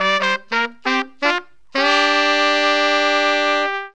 HORN RIFF 14.wav